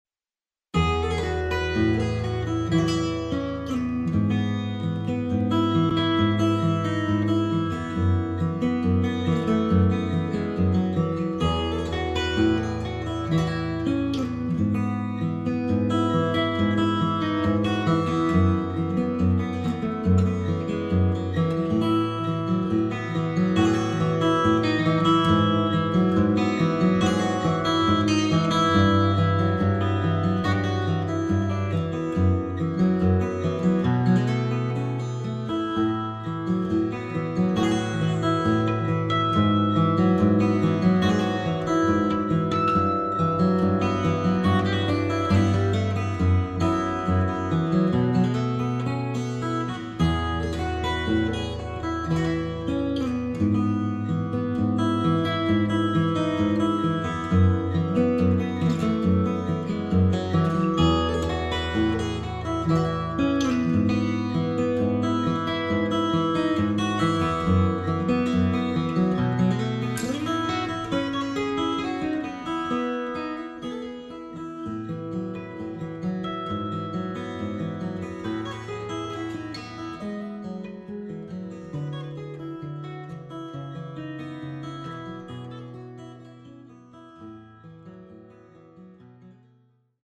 raccolta di dieci brani solisti nati dall’84 all’89